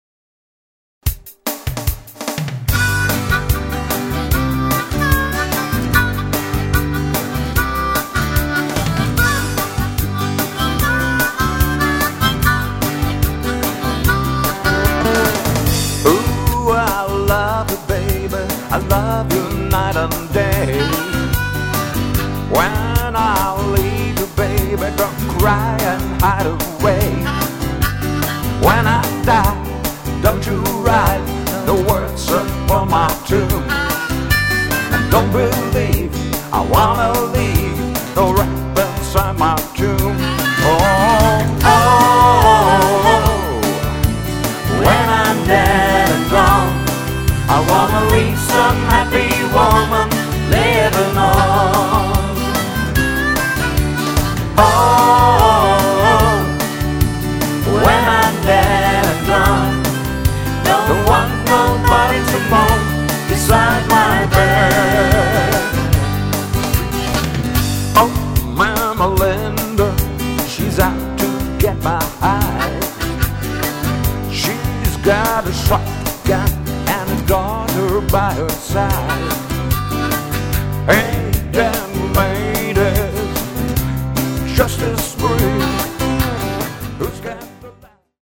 Folk-Rock